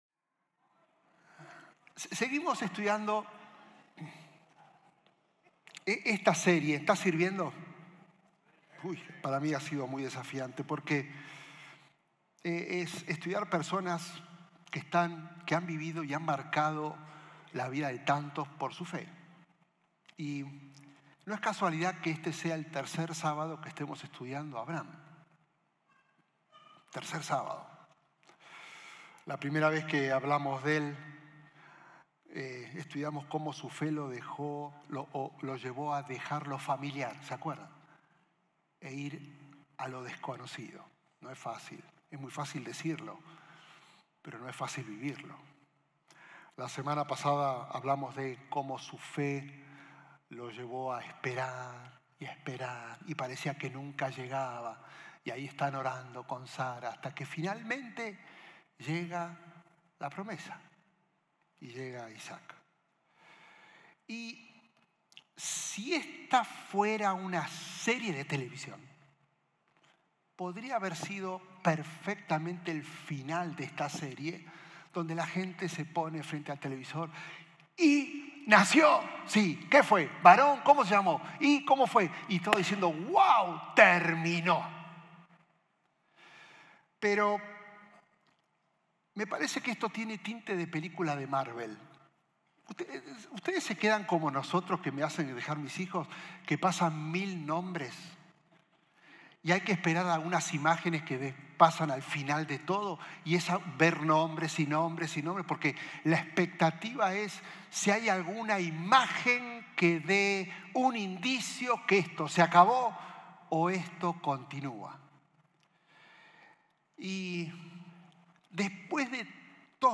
Un mensaje de la serie "Más - NK."